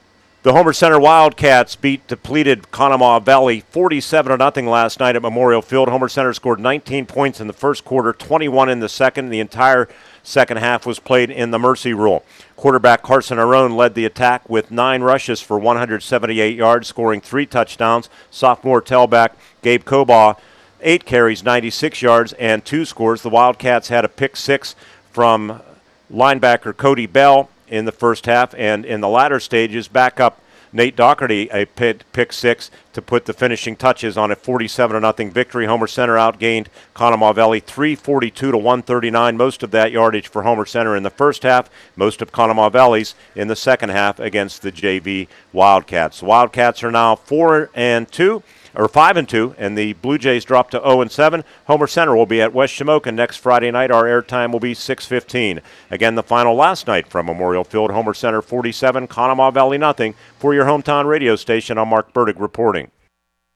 recap
10-3-25-cv-at-hc-recap.mp3